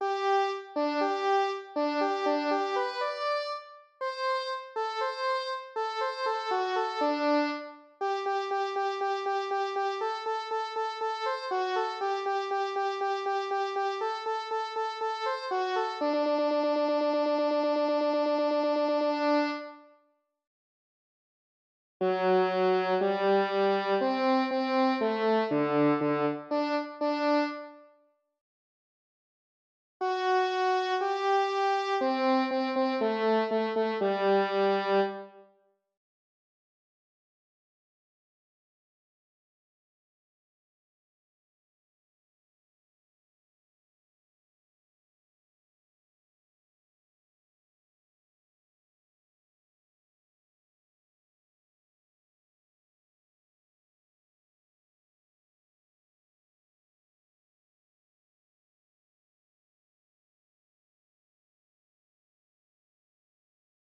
eine-kleine-cello-new.wav